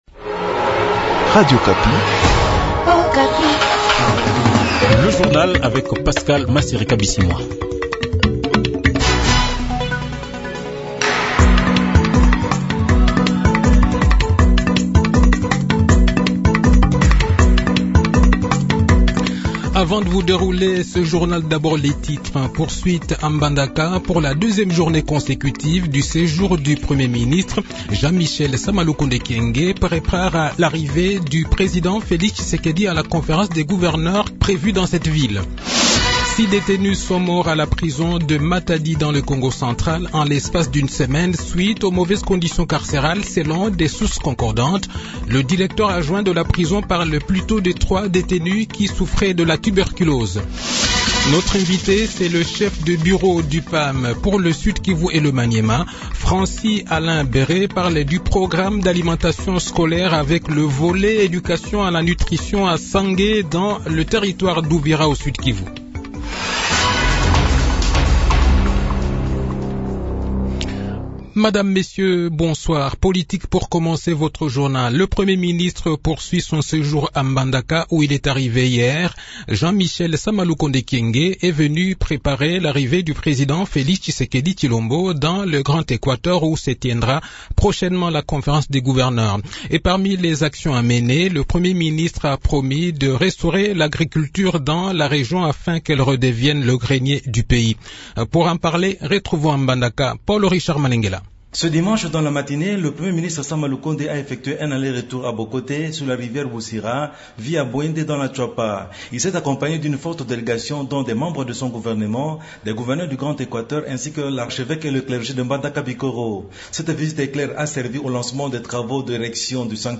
Journal Soir
Le journal de 18 h, 13 mars 2022